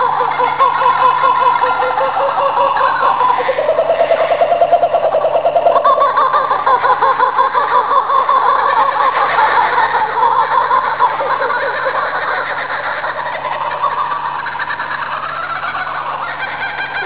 Kookaburra
Le kooka posséde un cri très caractéristique.